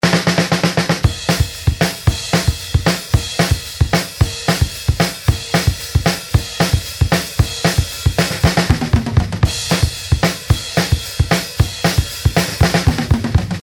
Kolega mi je poslal ta dva koscka (snema punk band od svojega brata) in povedal, da so imeli na voljo 2 vhoda na kartici EMU 0404 - snare in kick je posnel na en kanal (imel je shure kick mic in 57ko), dva overheada pa na drug kanal (mono) - bili sta 2 beta58 zmiksani na en kanal...
Lahko si nalozite in poskusate iz tega zelo home-recording scenarija potegnit maksimalno...